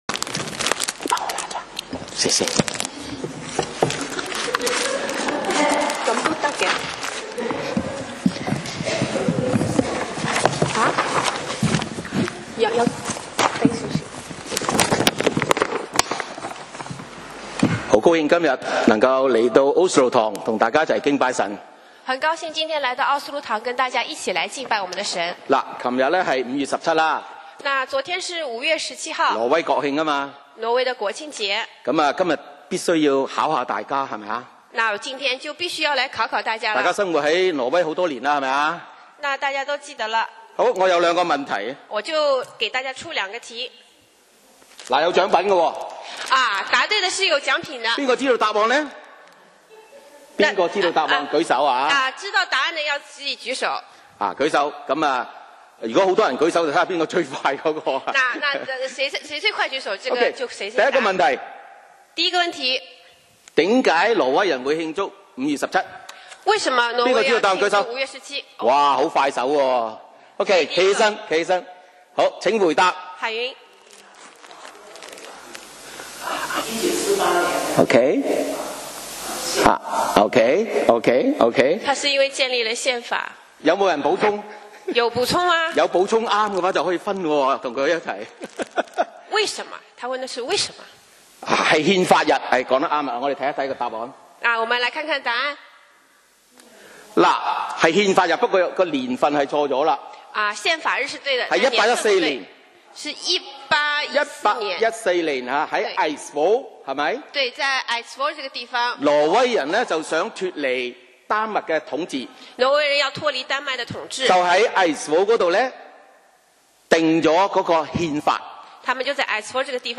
講道 Sermon 題目 Topic：5.17 挪威國慶與教會福音使命 經文 Verses：耶利米書 7：1-11，彌迦書6：8. 1 耶和华的话临到耶利米说，2你当站在耶和华殿的门口，在那里宣传这话说，你们进这些门敬拜耶和华的一切犹大人，当听耶和华的话。